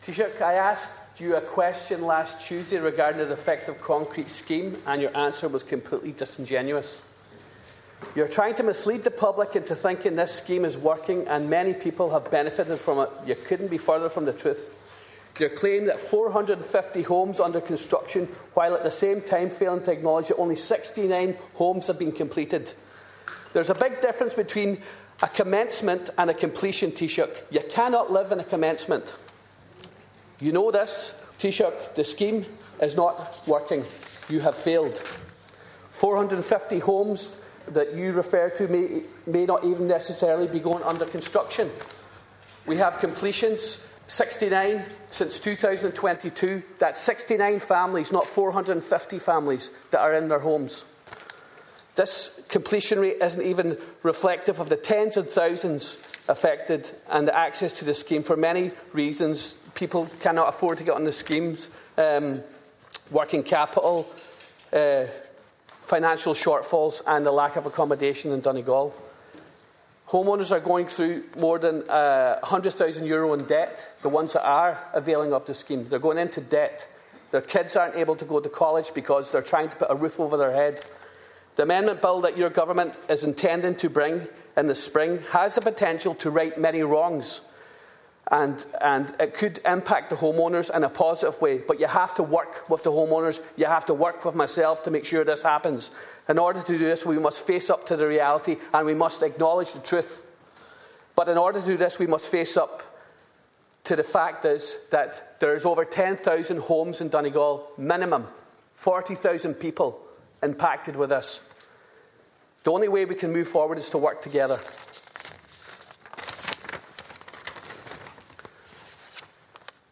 In the Dail, Deputy Ward urged Taoiseach to work together with those at the coalface of the crisis……………